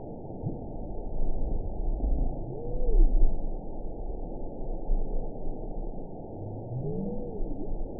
event 922338 date 12/30/24 time 02:01:56 GMT (11 months ago) score 9.03 location TSS-AB10 detected by nrw target species NRW annotations +NRW Spectrogram: Frequency (kHz) vs. Time (s) audio not available .wav